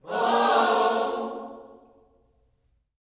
WHOA-OHS 4.wav